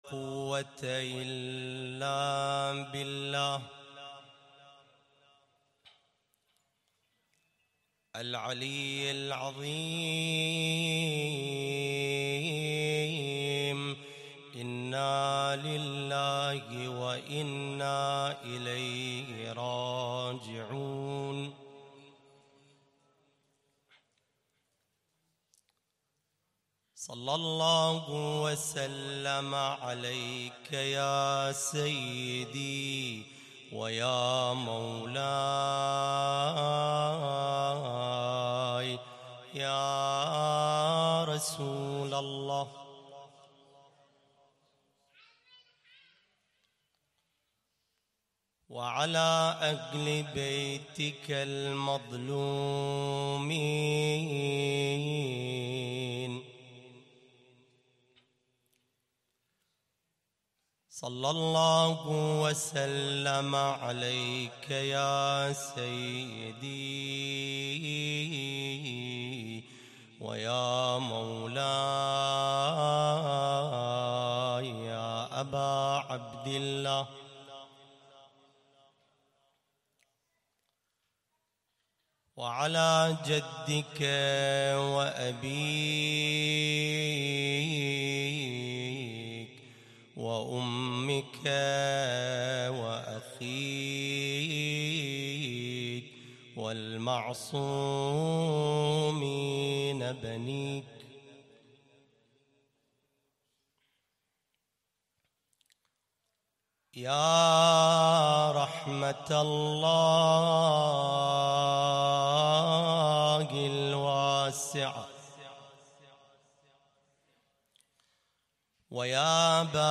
تغطية شاملة: المجلس الحسيني ليلة 16 رمضان 1440هـ
محاضرة